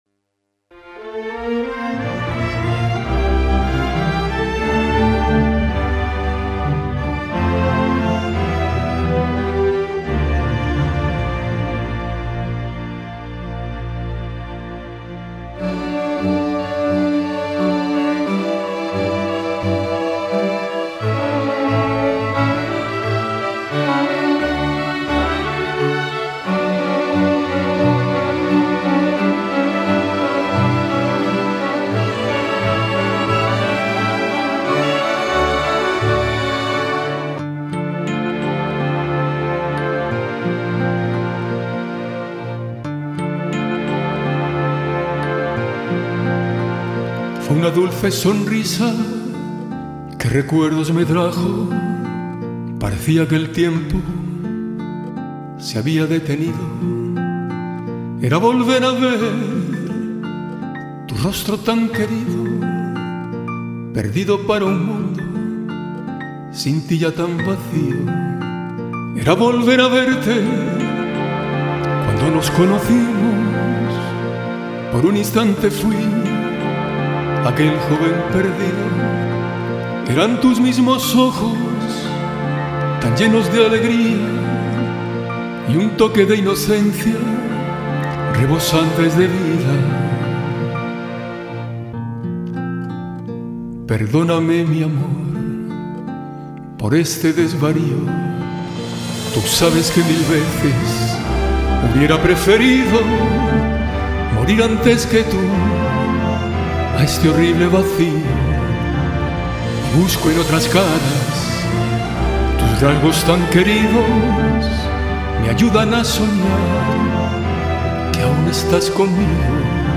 Una canción de tristeza asumida.